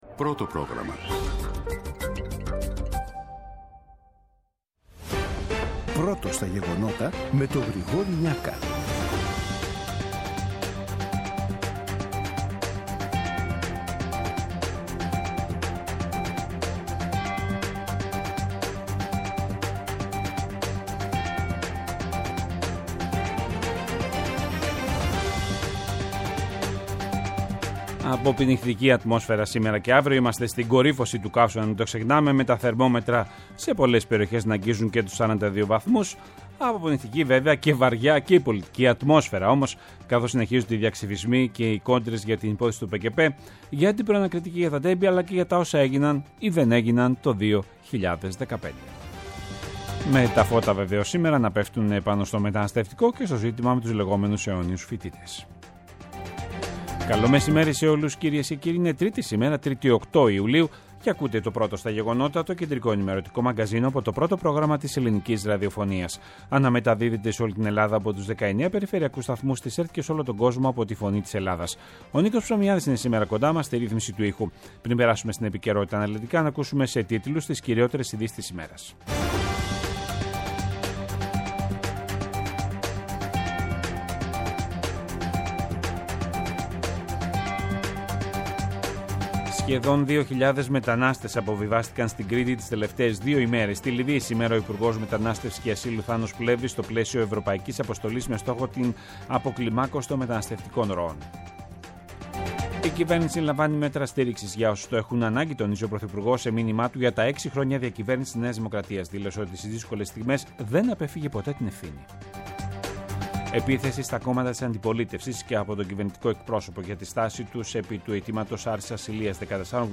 Το αναλυτικό ενημερωτικό μαγκαζίνο του Α΄ Προγράμματος στις 14:00. Με το μεγαλύτερο δίκτυο ανταποκριτών σε όλη τη χώρα, αναλυτικά ρεπορτάζ και συνεντεύξεις επικαιρότητας.